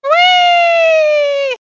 One of Diddy Kong's voice clips in Mario Kart: Double Dash!!